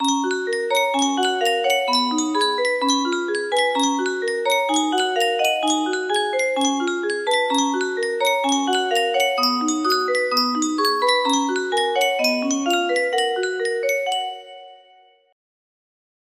Yunsheng Music Box - Salut D'amour 1799 music box melody
Full range 60